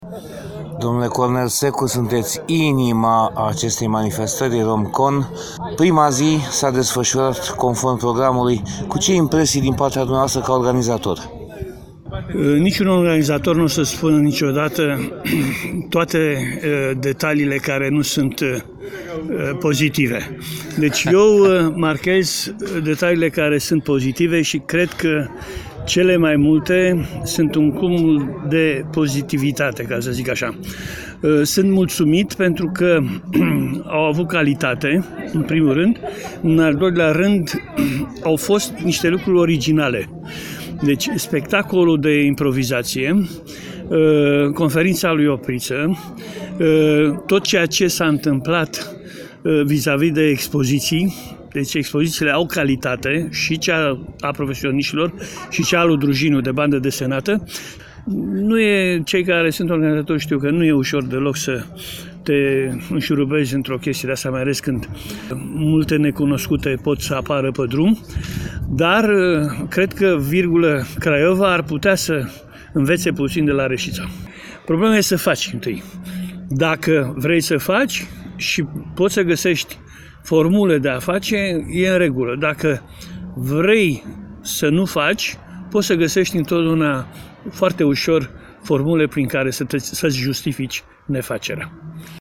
A 39-A CONVENȚIE NAȚIONALĂ DE SCIENCE-FICTION s-a desfășurat în perioada 7-9 SEPTEMBRIE 2018, la UNIVERSITATEA “EFTIMIE MURGU” REȘIȚA.